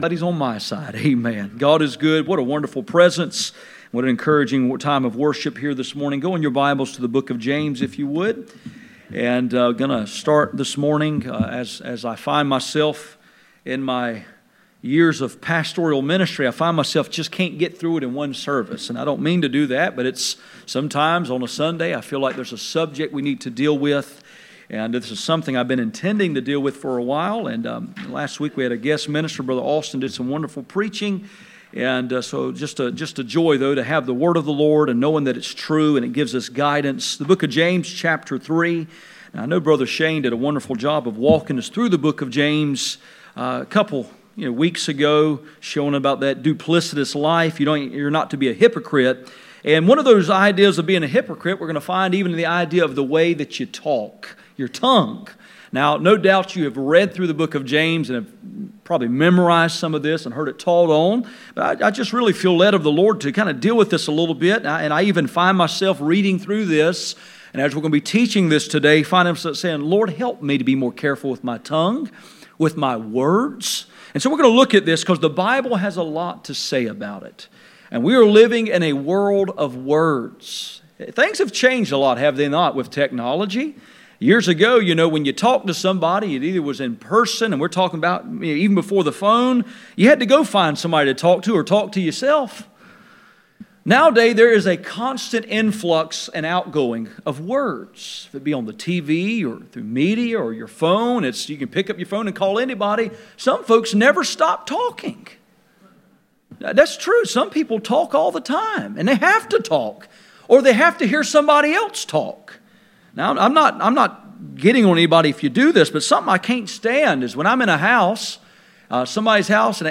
James 3:1-12 Service Type: Sunday Morning %todo_render% « Kill it while it is yet young Controlling the Tongue